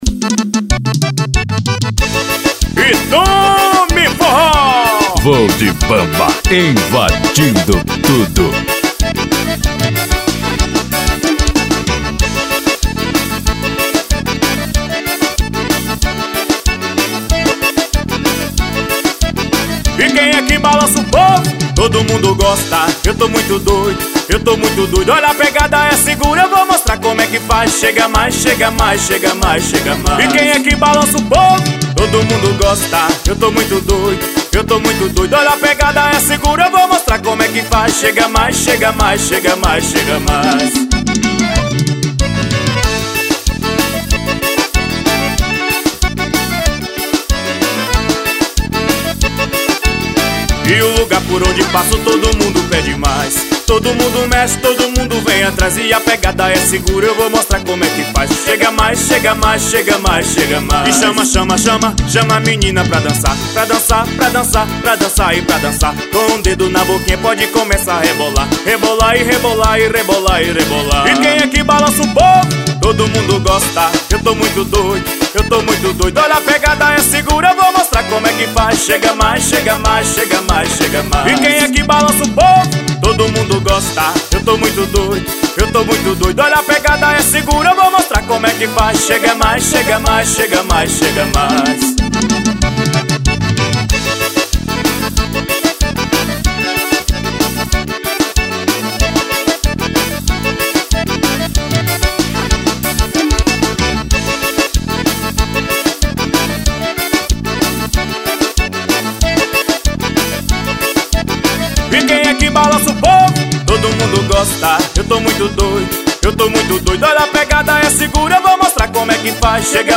(forró).